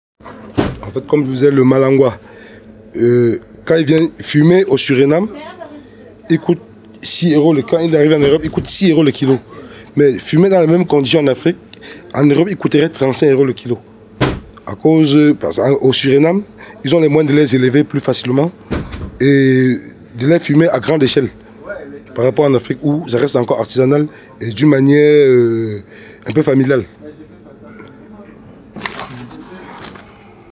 prononciation Le malangua.